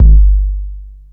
• Old School Tight Low End Bass Drum Single Shot G Key 405.wav
Royality free kick drum single hit tuned to the G note. Loudest frequency: 56Hz
old-school-tight-low-end-bass-drum-single-shot-g-key-405-J92.wav